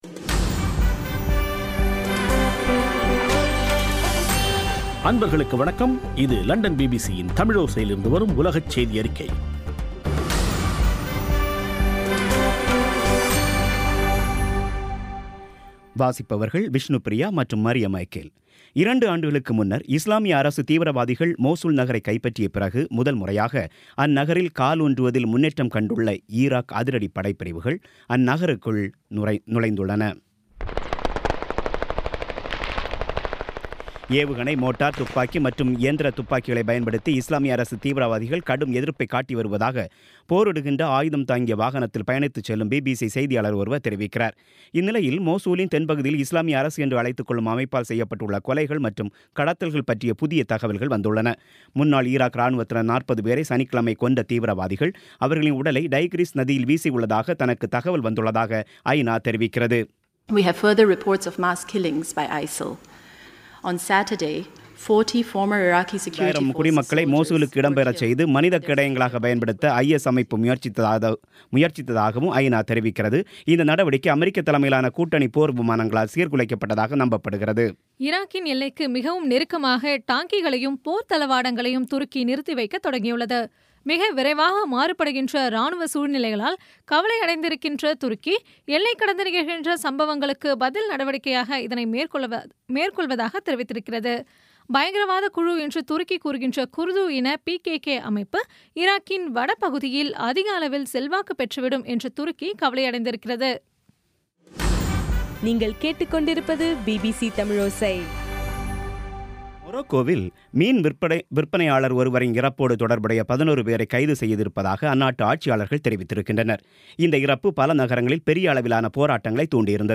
இன்றைய (நவம்பர்1ம் தேதி ) பிபிசி தமிழோசை செய்தியறிக்கை